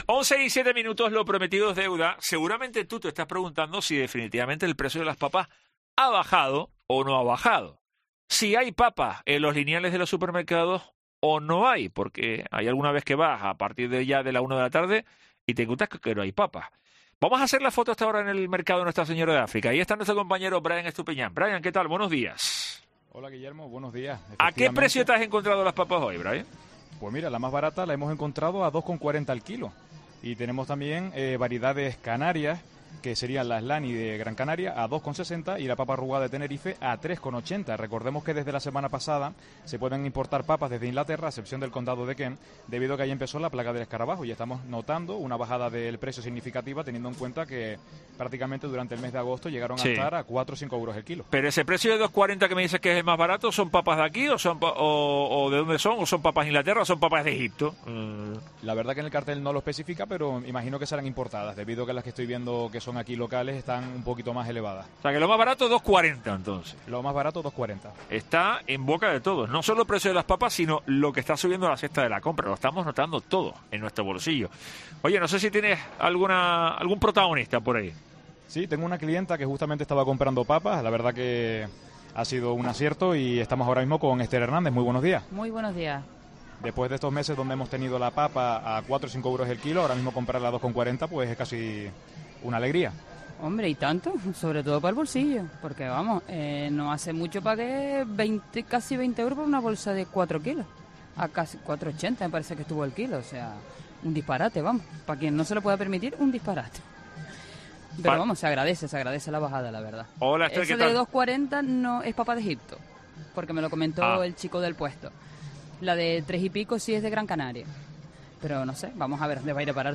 Comprobamos en el mercado cuánto ha bajado el precio de las papas en Tenerife
Herrera en COPE Tenerife ha visitado el Mercado de Nuestra Señora de África, en Santa Cruz, para conocer de primera mano la bajada de precios con la llegada de estas importaciones.